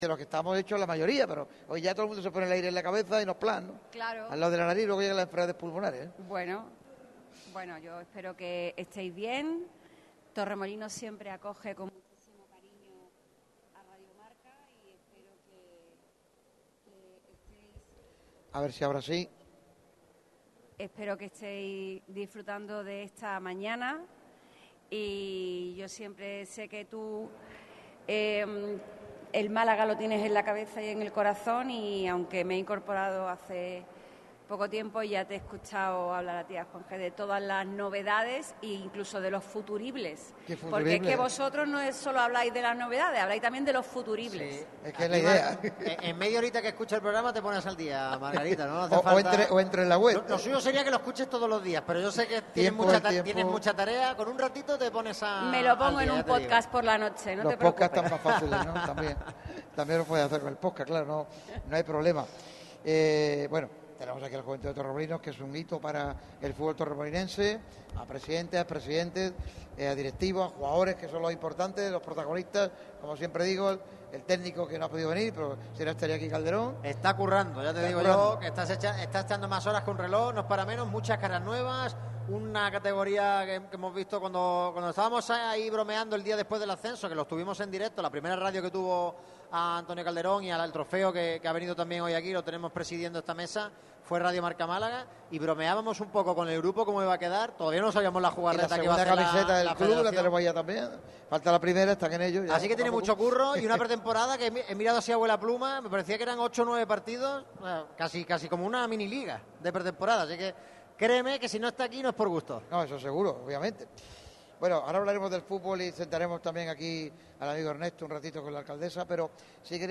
La alcaldesa de Torremolinos, Margarita del Cid, ha sido una de las grandes protagonistas del especial programa que Radio MARCA Málaga ha realizado en la localidad costasoleña. El nuevo Centro de Desarrollo Comunitario Isabel Manoja ha acogido la entrega del programa en este jueves 10 de julio. La regidora del consistorio torremolinense ha hecho repaso de la actualidad de la ciudad y también ha hablado sobre la política a nivel nacional.